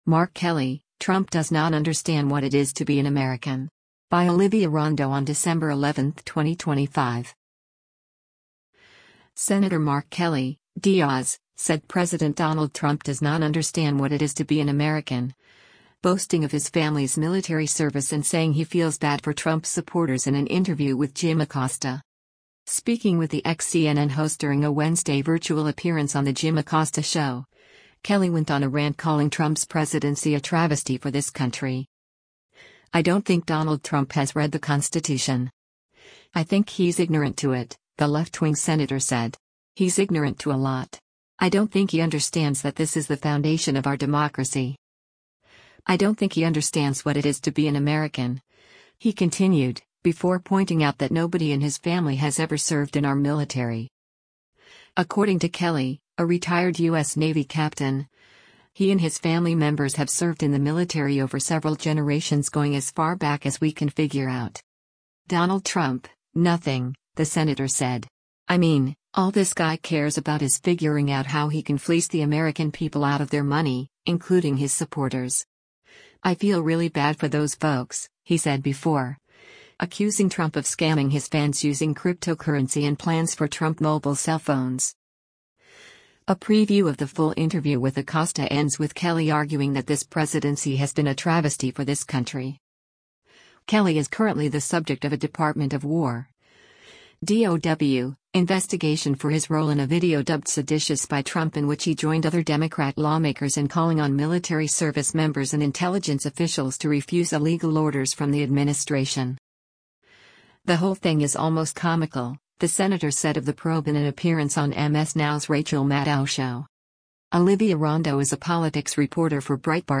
Sen. Mark Kelly (D-AZ) said President Donald Trump does not “understand what it is to be an American,” boasting of his family’s military service and saying he feels “bad” for Trump’s supporters in an interview with Jim Acosta.
Speaking with the ex-CNN host during a Wednesday virtual appearance on the Jim Acosta Show, Kelly went on a rant calling Trump’s presidency a “travesty for this country.”